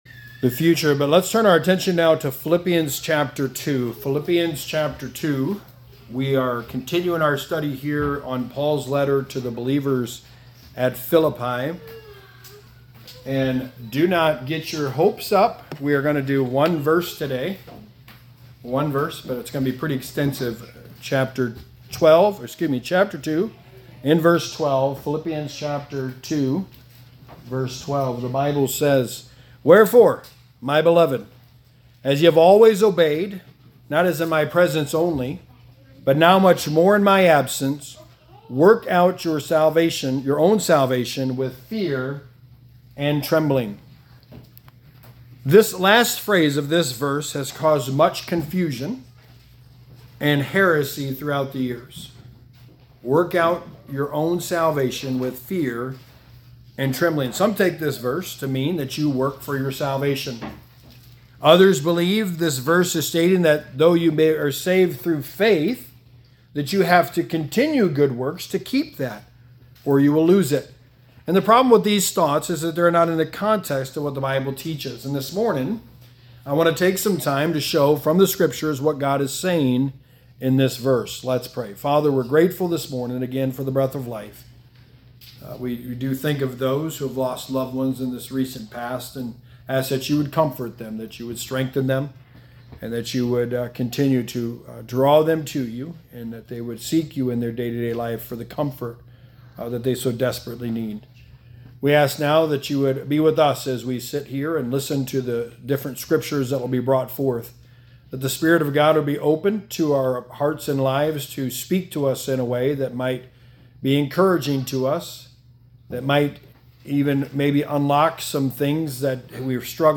Sermon 12: The Book of Philippians: Work Out Your Own Salvation
Service Type: Sunday Morning